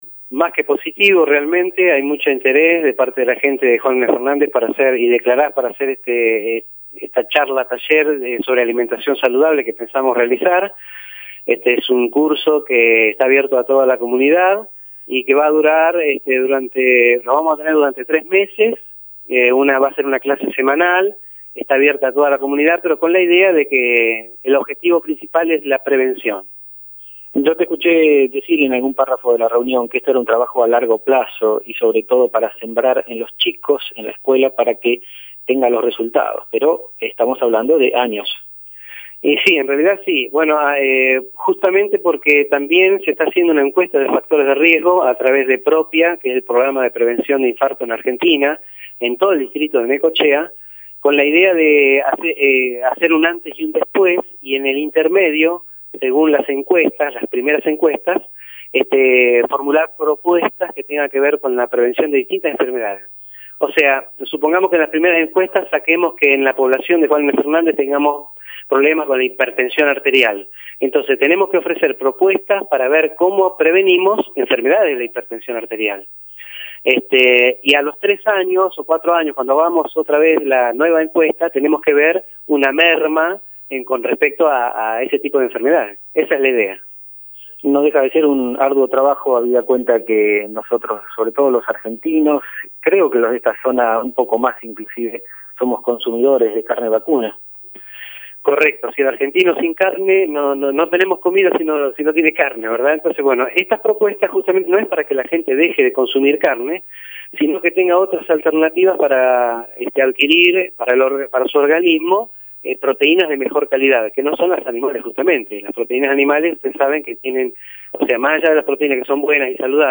En diálogo con JNFNet